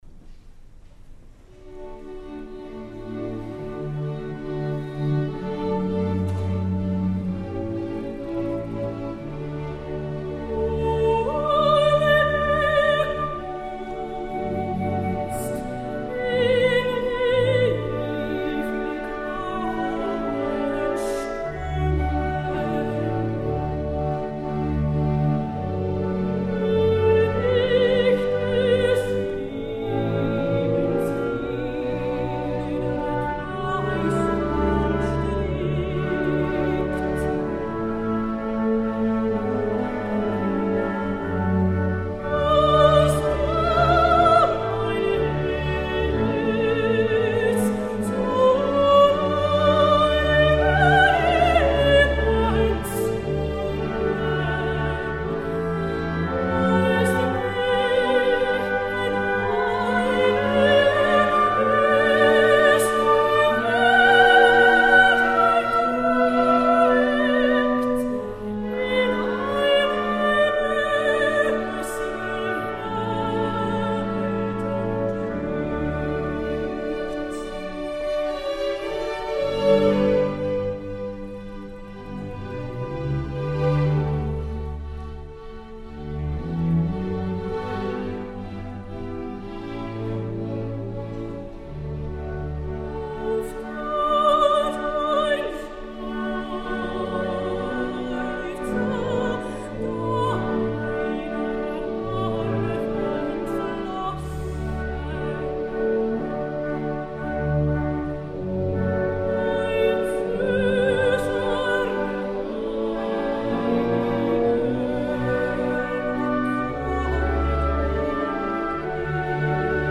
“An die Musik” για Σoπράνο και Ορχήστρα (live)